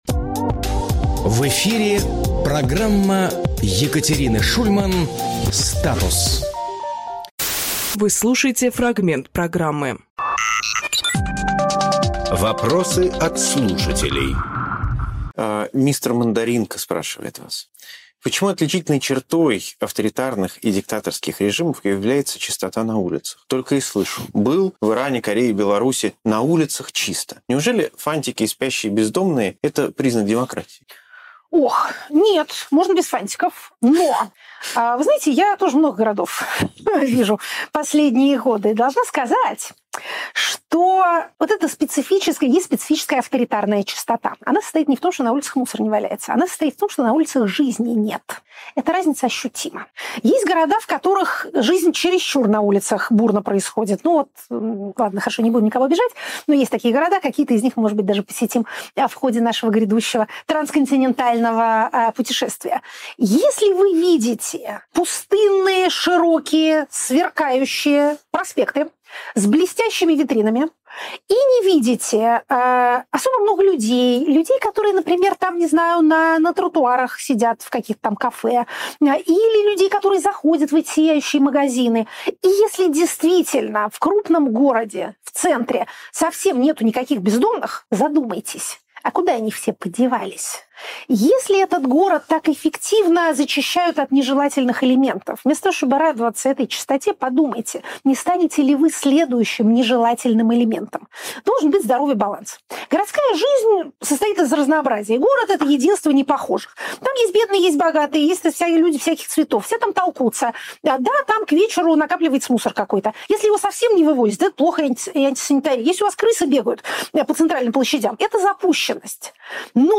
Фрагмент эфира от 24.03.26